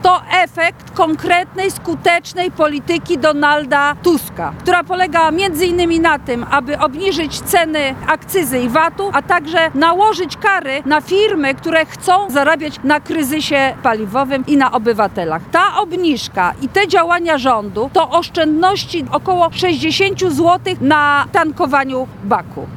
– Na tej stacji jeszcze tydzień temu cena za 95 wynosiła 7zł 59 gr, a oleju napędowego 8 zł 79 gr – mówiła na jednaj ze stacji paliw w Lublinie europosłanka Koalicji Obywatelskiej, Marta Wcisło.